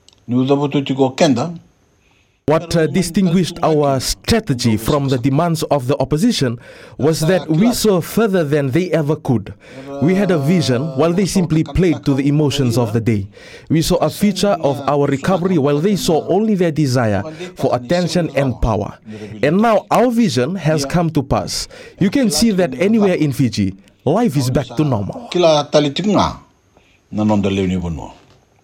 Speaking on the iTaukei Affairs Na iLalakai program on Radio Fiji One, Prime Minister Voreqe Bainimarama says the government negotiated highly concessional loans at zero percent to point zero one percent interest rates from reputable financial institutions such as the World Bank.